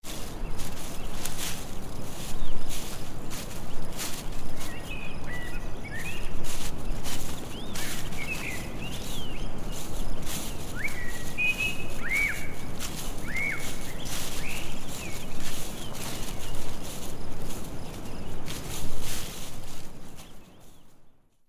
Caminar campo
Sonido de unos pasos caminando por el campo, en la naturaleza